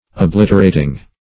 Obliterating - definition of Obliterating - synonyms, pronunciation, spelling from Free Dictionary